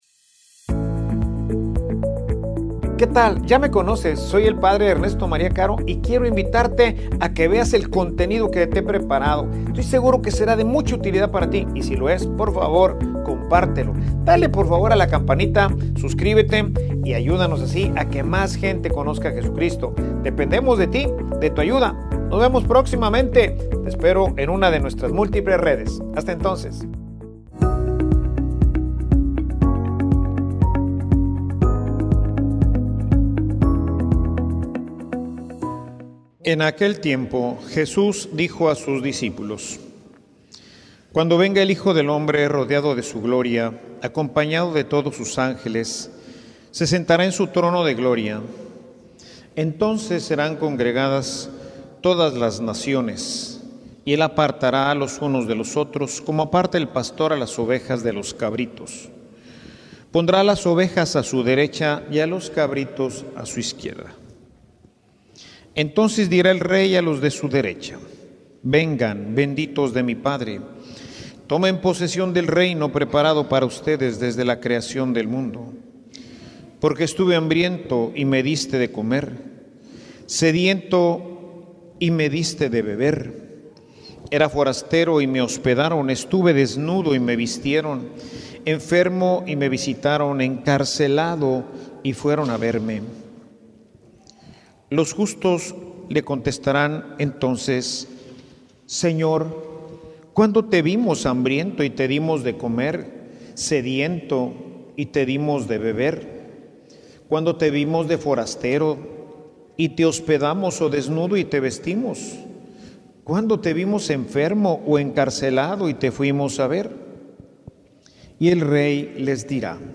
Homilia_Decisiones_que_nos_llevan_a_la_felicidad.mp3